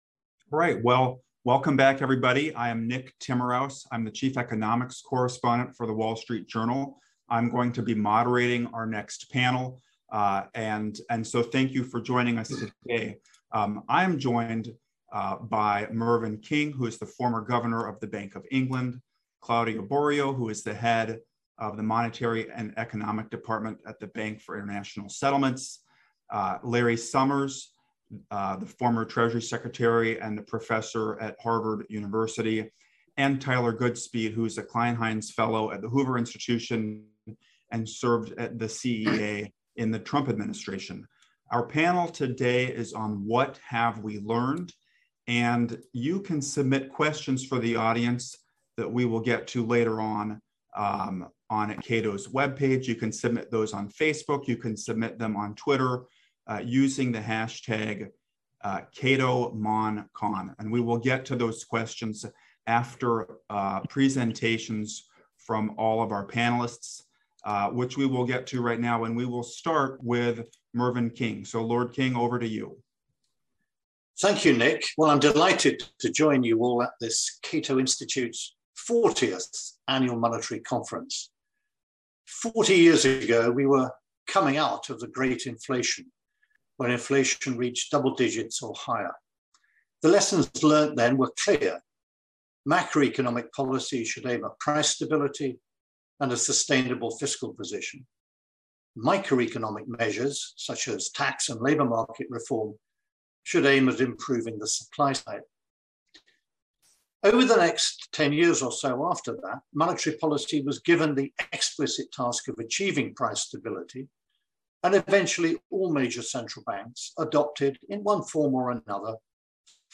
Panel 1: What Have We Learned? - Cato Institute 40th Annual Monetary Conference